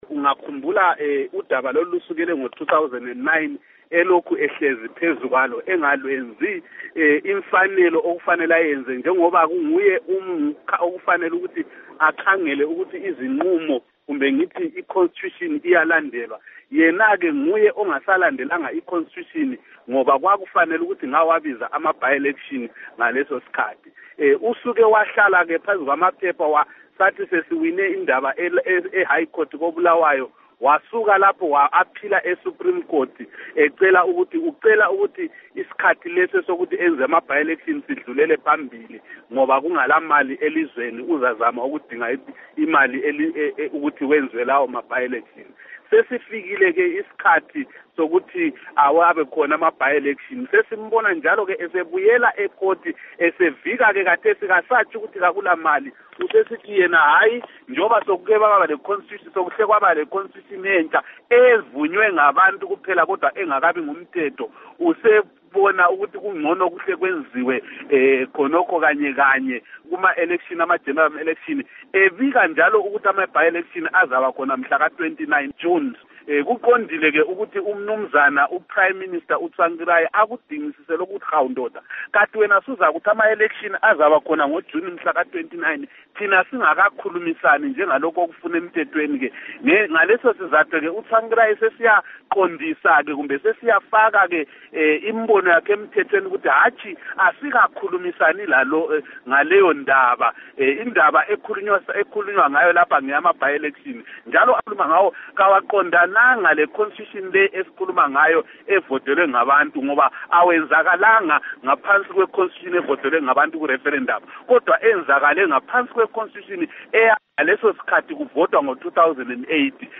Embed share Ingxoxo Esiyenze LoMnu.